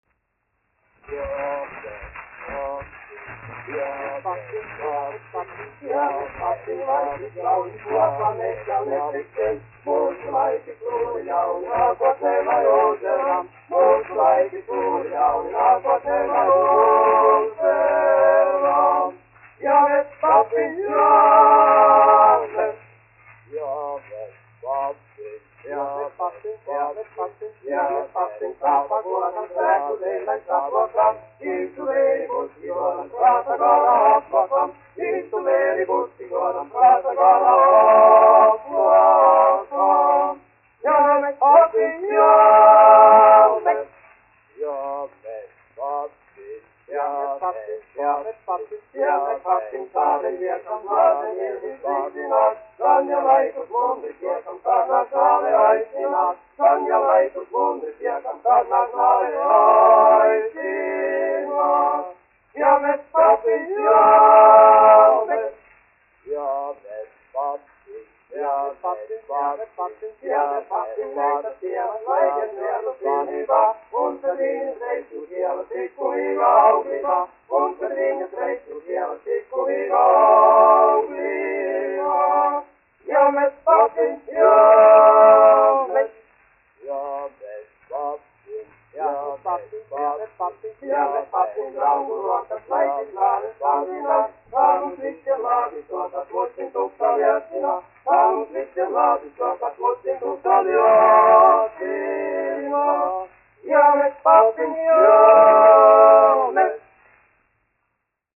1 skpl. : analogs, 78 apgr/min, mono ; 25 cm
Vokālie kvarteti
Vīru kvartets
Latvijas vēsturiskie šellaka skaņuplašu ieraksti (Kolekcija)